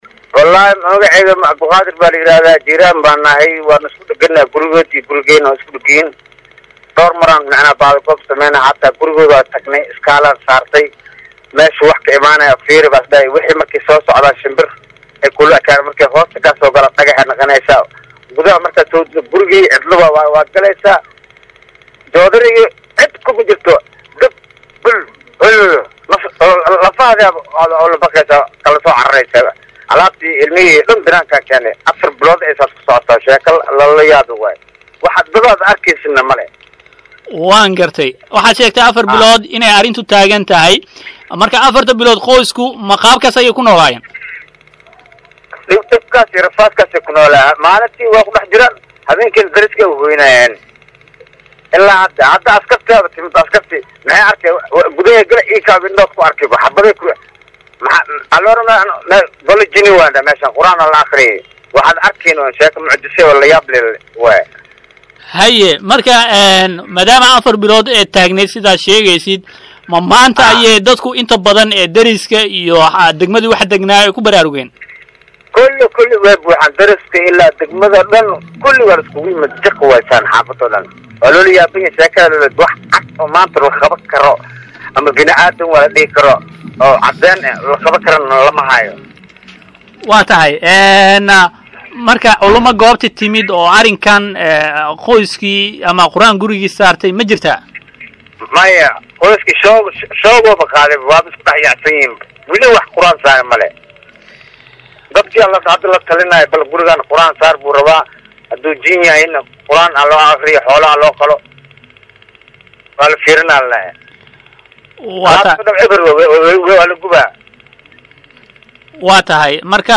Guri ku yaala Muqdisho oo habeenkii dadku ayna seexan maxaase sababay: WARAYSI
Guri ku yaala Muqdisho oo habeenkii dadku ayna seexan muddo afar bilooda. Ninka waraysi bixiyay ayaa hadley sida sheekadu ku bilaabantey iyo waxa lagu sameeyo.